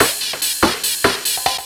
DS 144-BPM A6.wav